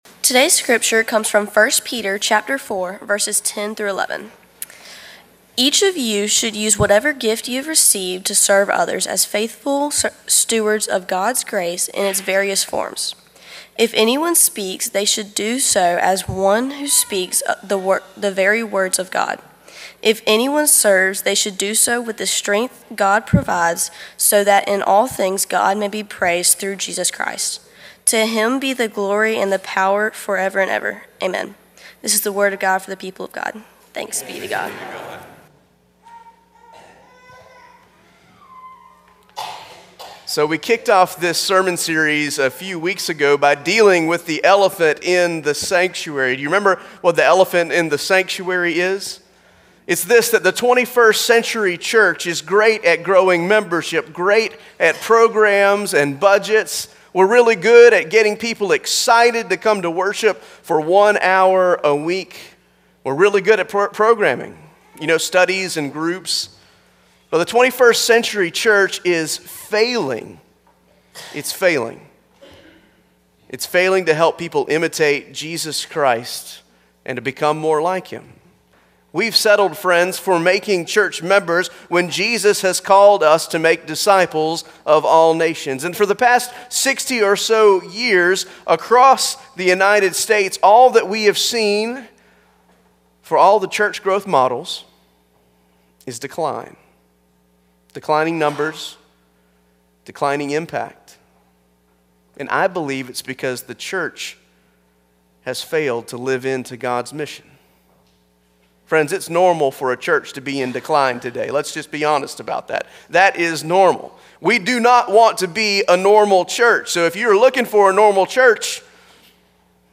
Passage: 1 Peter 4: 10-11 Service Type: Traditional Service